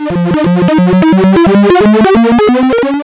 anger.wav